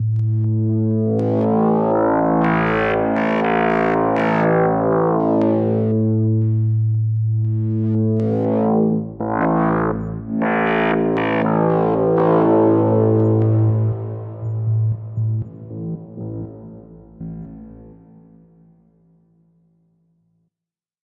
fm buchla aalto无人机
描述：Madrona Labs的Aalto FM合成器。
Tag: FM收音机 合成器 无人驾驶飞机 失谐 空间 噪音